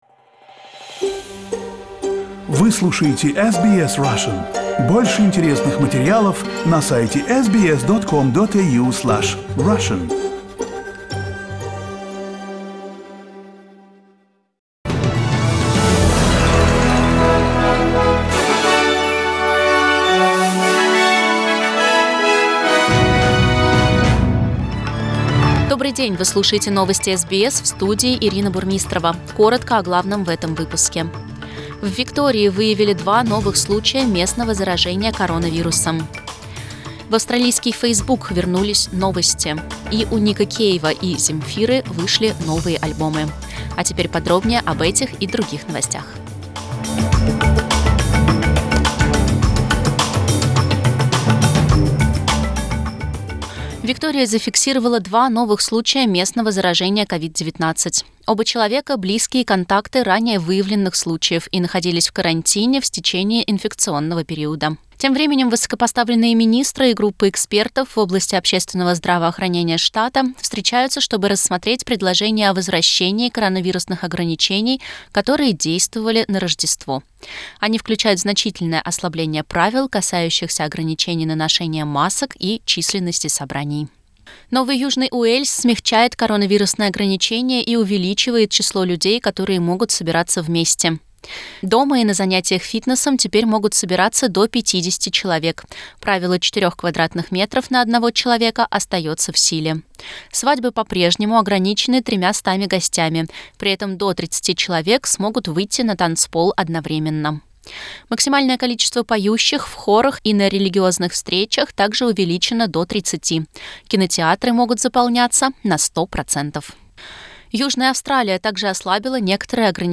News bulletin in Russian, February, 26